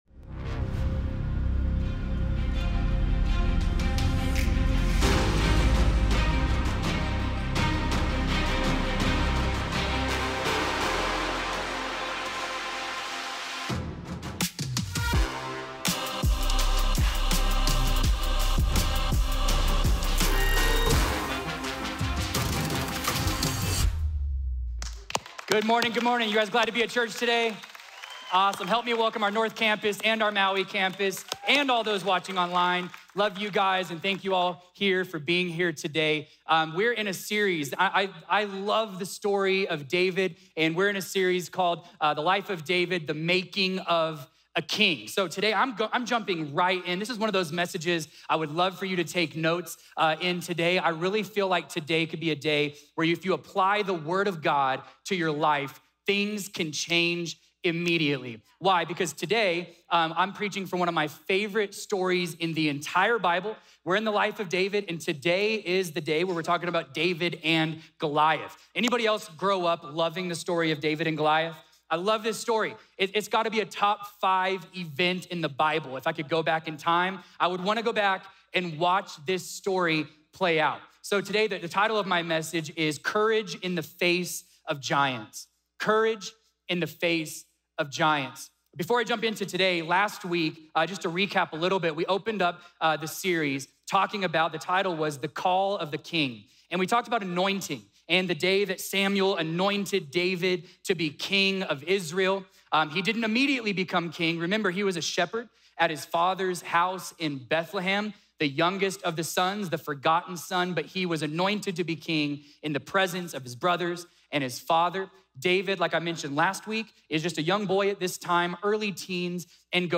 A message from the series "Summer on the Mount."